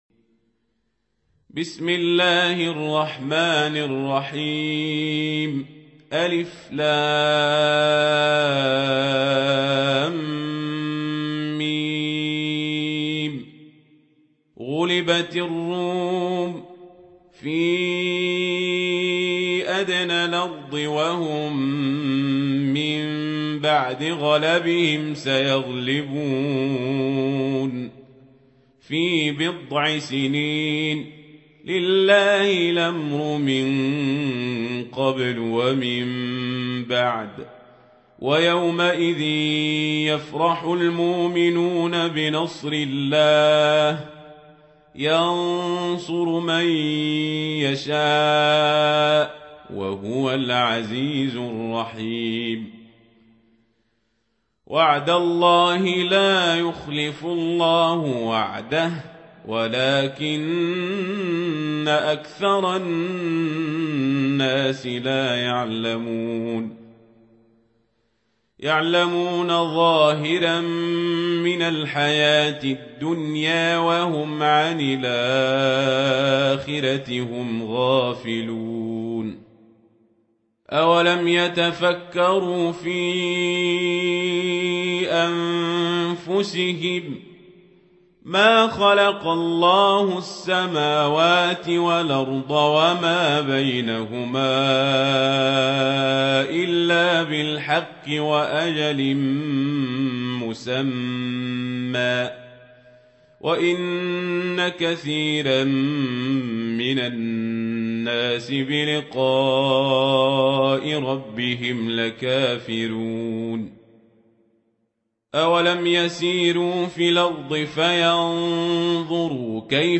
سورة الروم | القارئ عمر القزابري